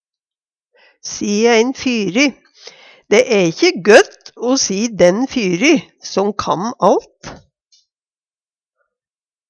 si ein fyry - Numedalsmål (en-US)